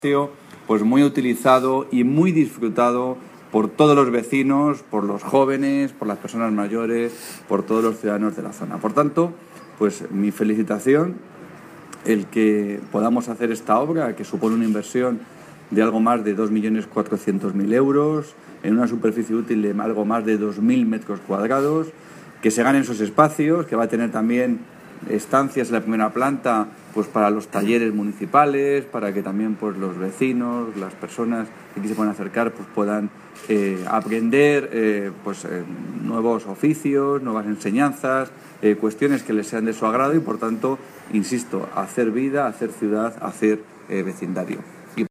Audio - Salvador Victoria (Consejero de Presidencia y Portavoz del Gobierno regional) Sobre Remodelación CC El Soto